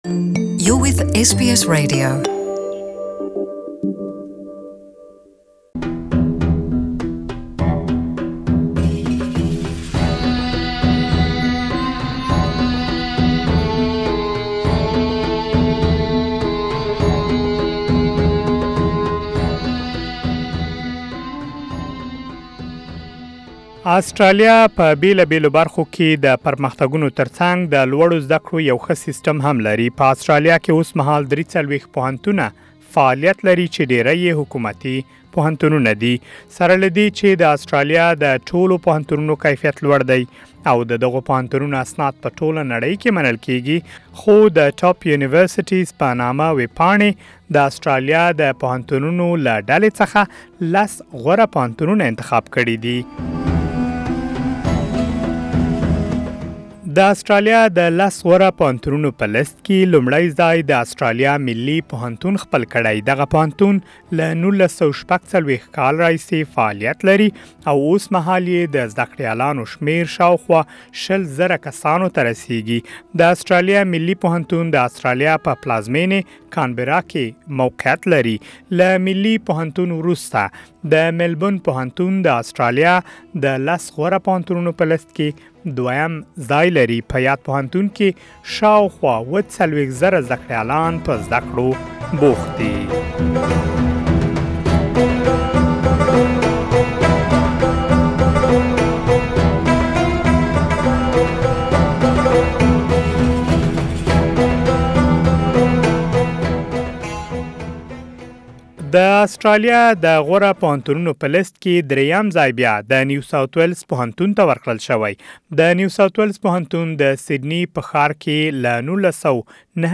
According to the Quacquarelli Symonds, the Australian National University is ranked as the best university in 2018. University of Melbourne and University of New South Wales are ranked as the second and third top universities in Australia. For more details, please listen to the full report in Pashto.